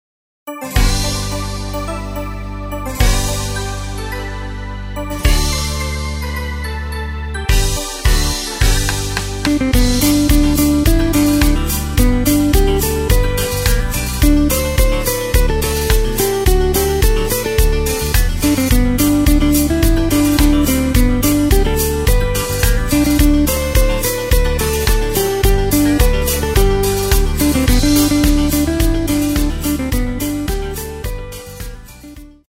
Takt:          4/4
Tempo:         107.00
Tonart:            G
Country Song aus dem Jahr 1986!